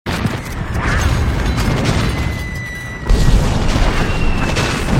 • EXPLOSIONS POPPING AND WHIRING.mp3
Big distant explosions wiring in the field, different reverb tails coming out from everywhere.
explosions_popping_and_whiring_xof.wav